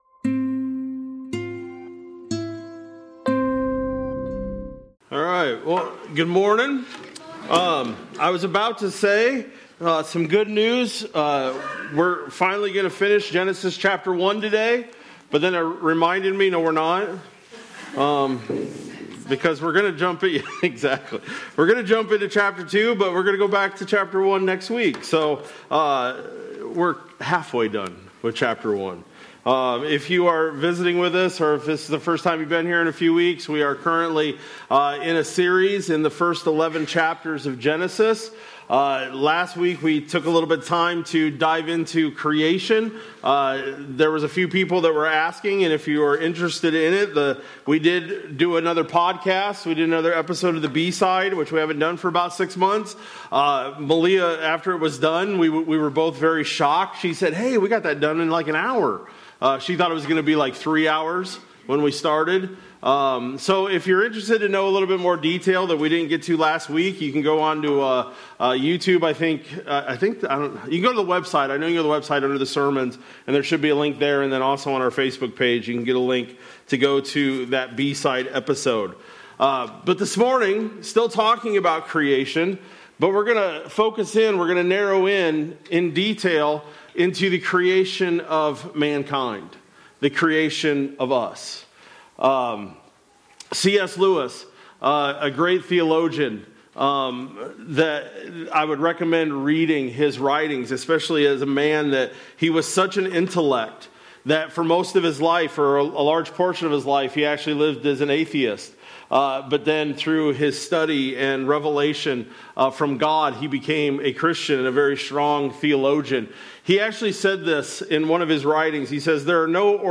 Feb-1-26-Sermon-Audio.mp3